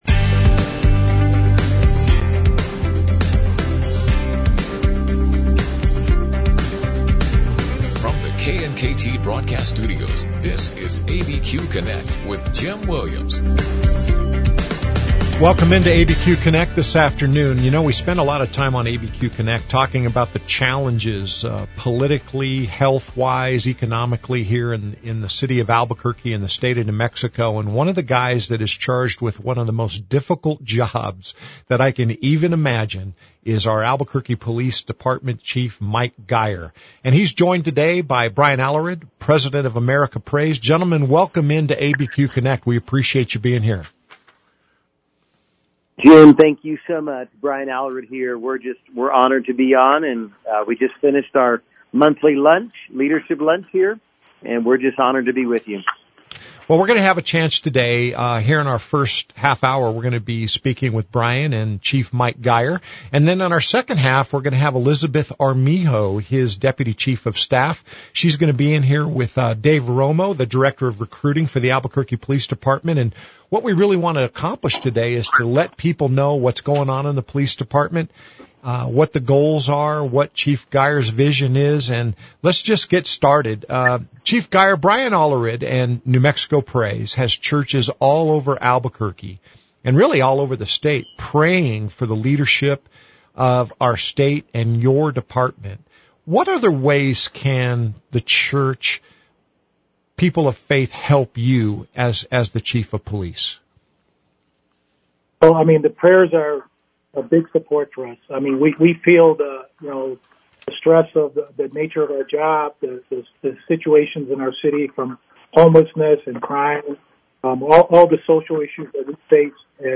Albuquerque's live and local call-in show.